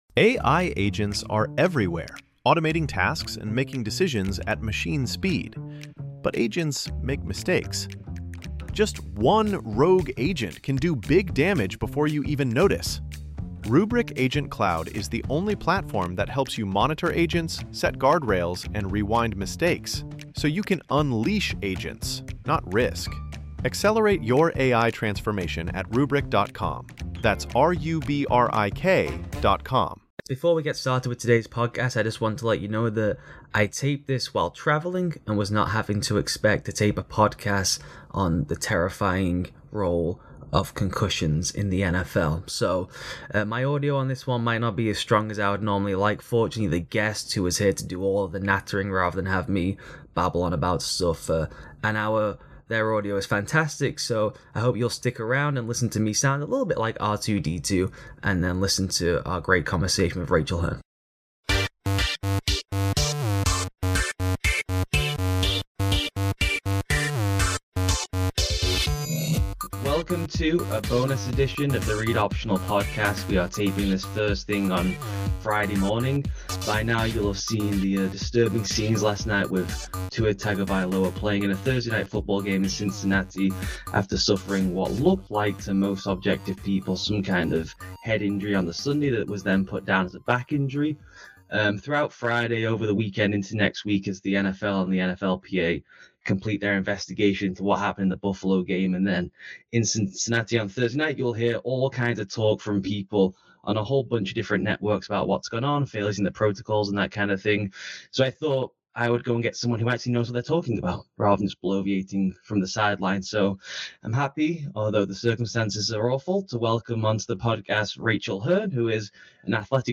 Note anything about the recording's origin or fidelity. makes him sound like a robot on this one!